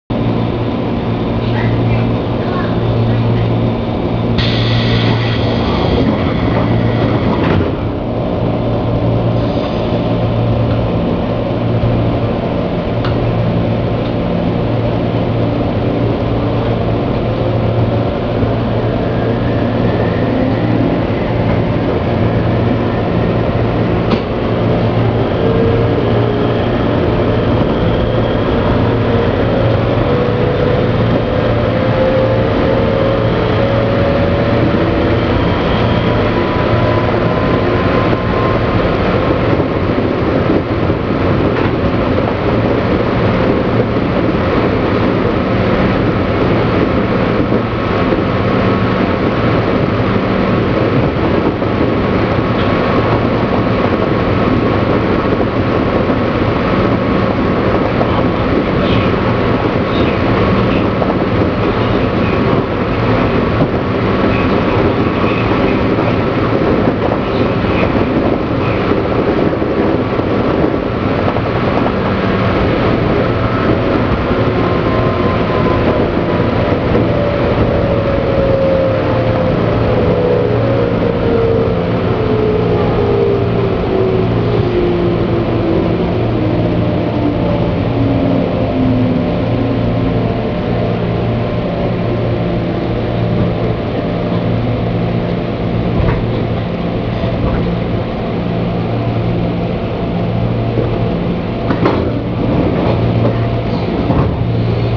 ・203系走行音
【常磐線】北小金〜新松戸（1分45秒：571KB）
車体が時々ミシミシ音を立てているのはやはり劣化が進んでいる証拠なのでしょうか。基本的に、音は201系と全く同じですが、走行中はアルミ車体故か、軽いドアが揺れに揺れてしまっています。